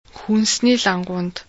Dies ist das normale Sprechtempo!